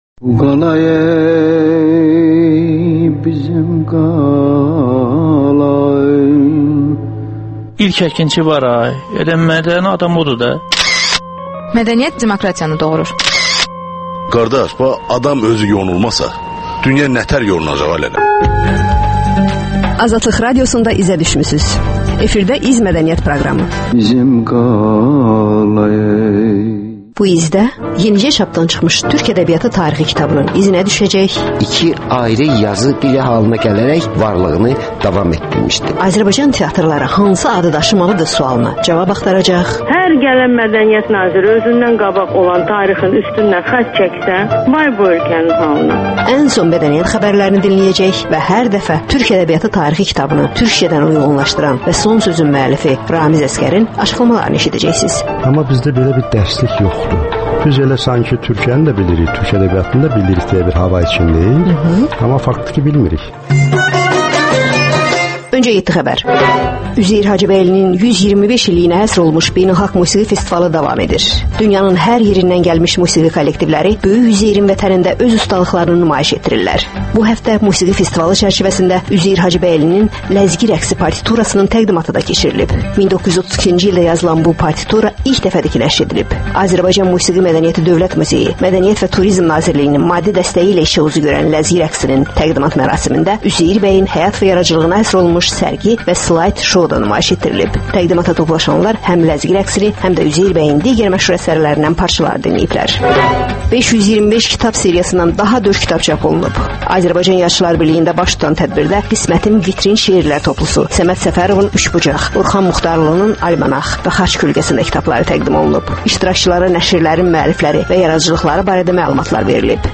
Ölkənin tanınmış simalarıyla söhbət